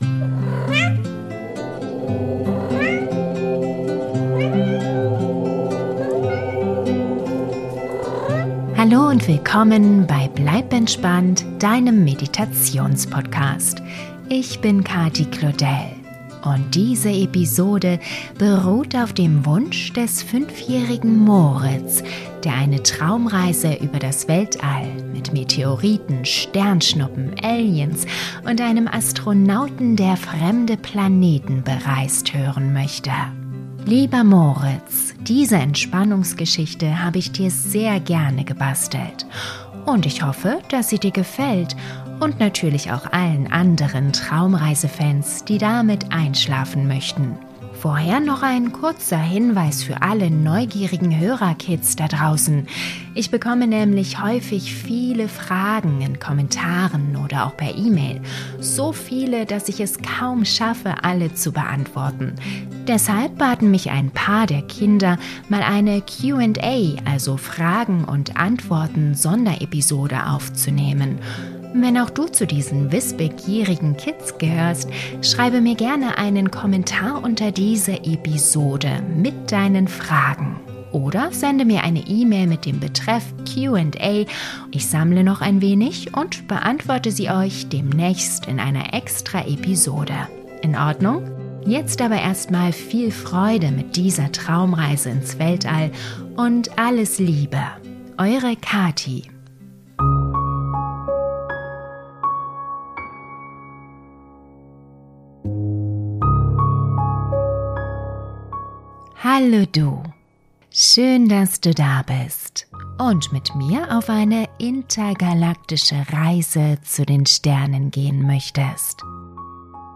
Traumreise für Kinder & Erwachsene ins Weltall - Der Kristallplanet - Weltraum Geschichte über Außerirdische